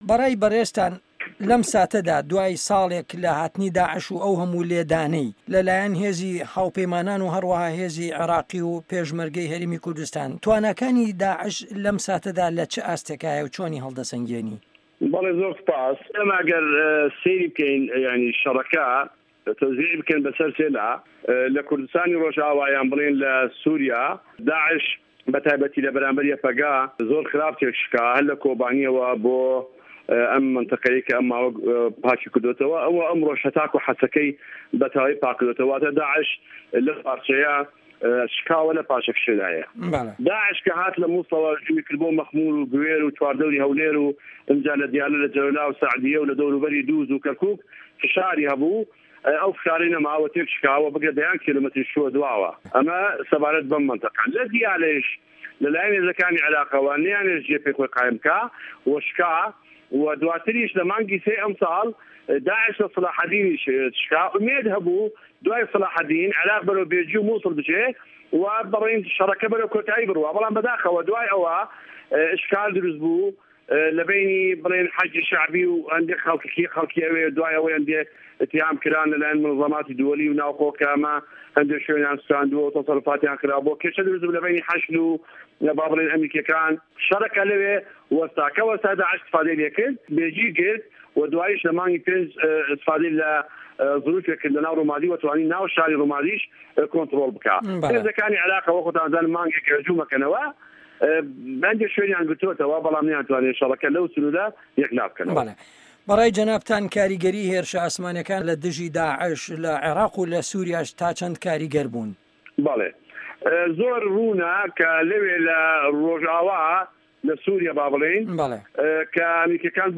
وتوێژ له‌گه‌ڵ حه‌سه‌ن جیهاد